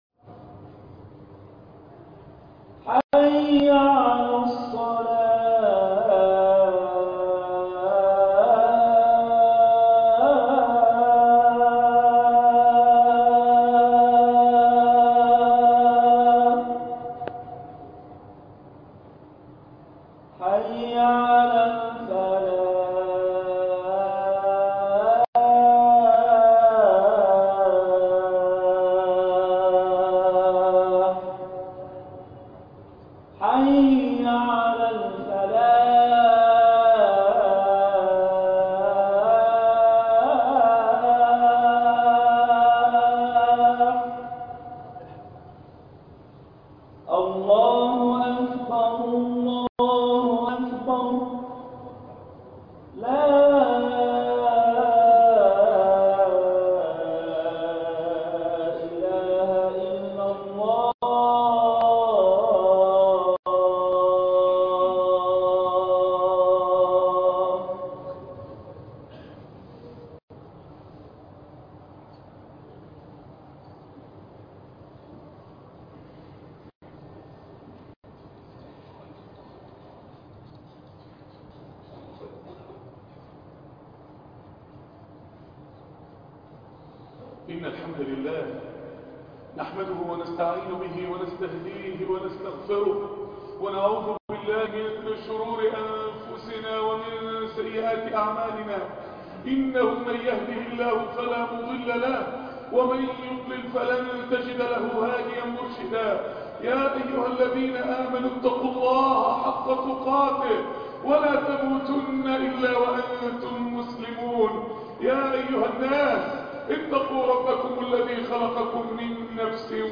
الهداف - .. خطبة الجمعة ..